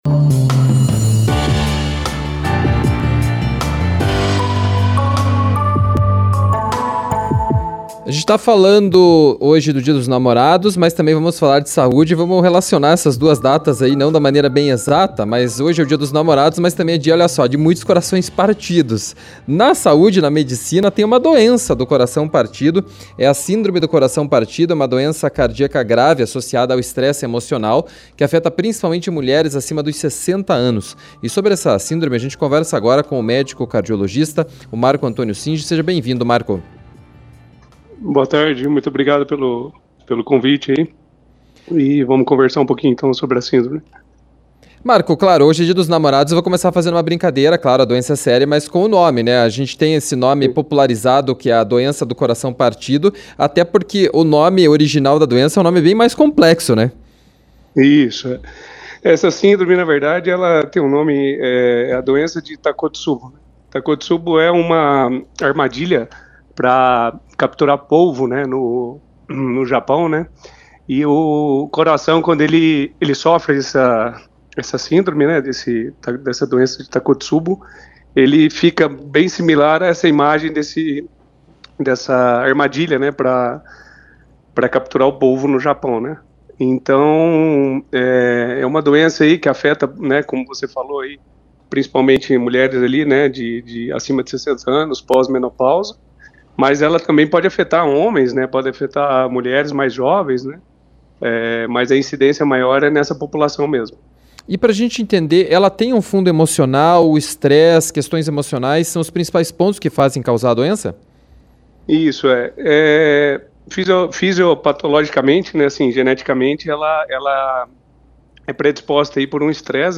conversou com o cardiologista